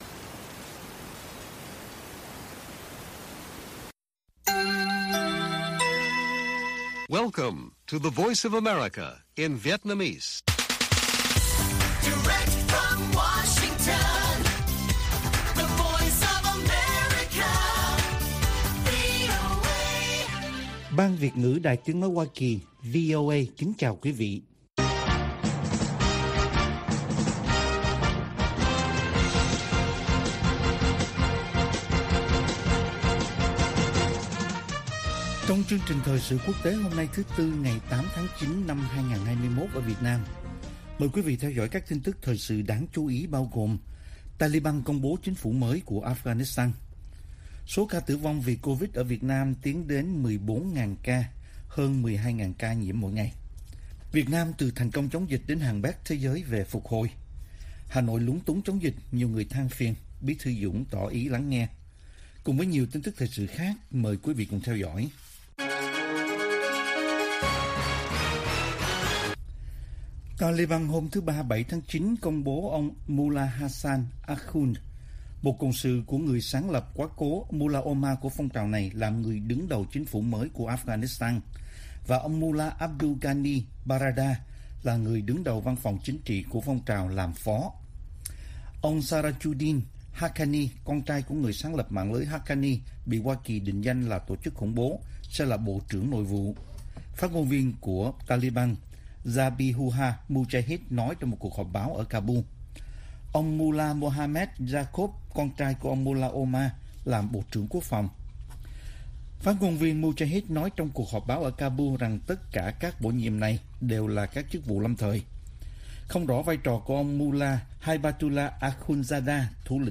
Bản tin VOA ngày 08/9/2021